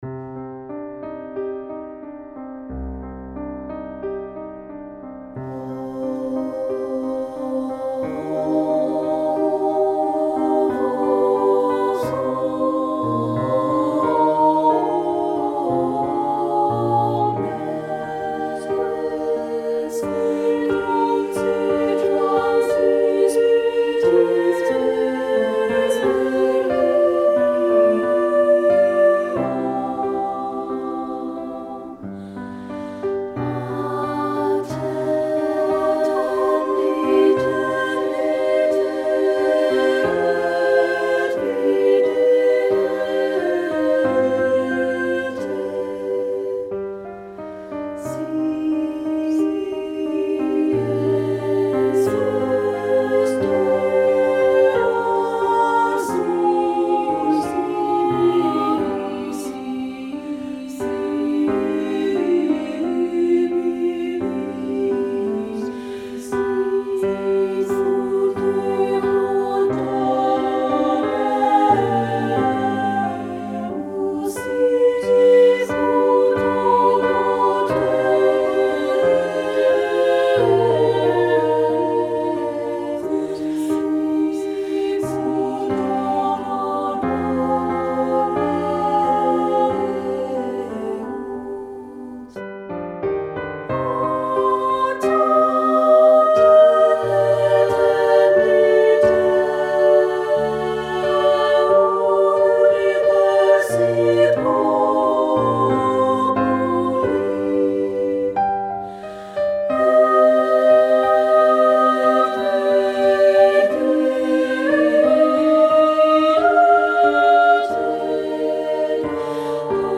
Voicing: SSA a cappella